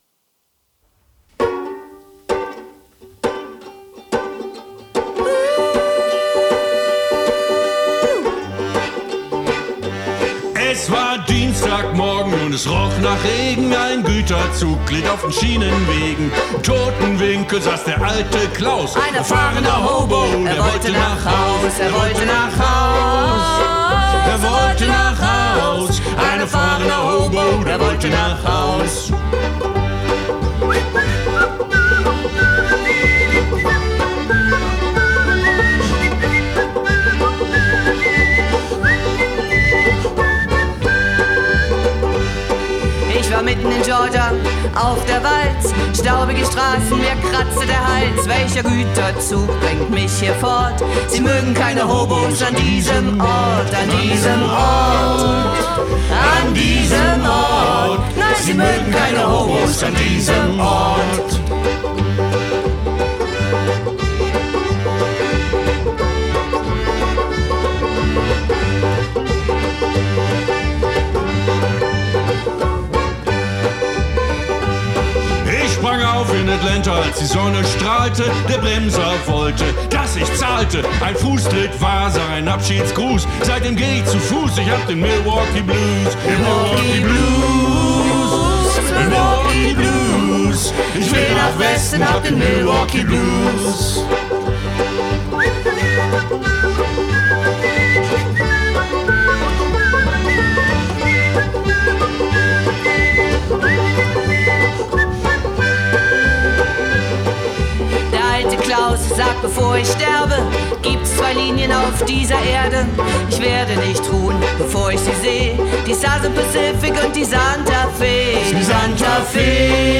fresh and rough German version
Hillbilly-Classic